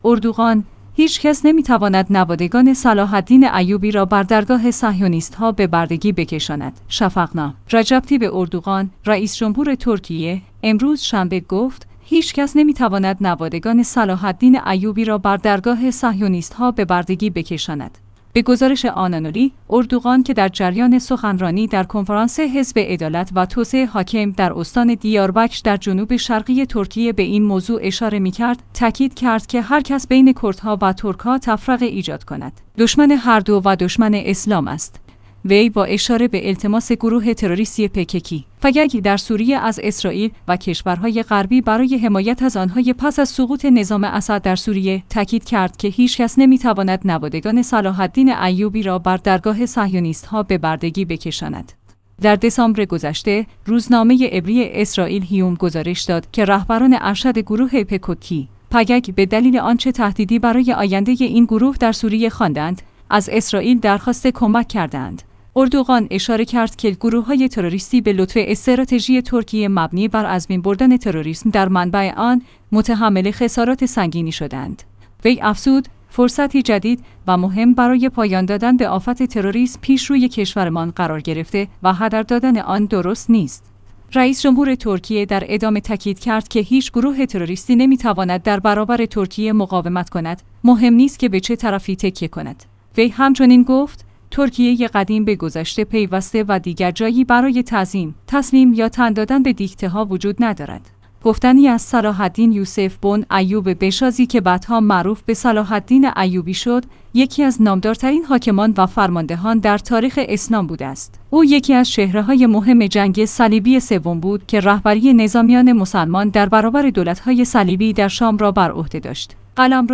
به گزارش آنانولی، اردوغان که در جریان سخنرانی در کنفرانس حزب عدالت و توسعه (حاکم) در استان دیاربکر در جنوب شرقی ترکیه به این موضوع اشاره می کرد، تاکید کرد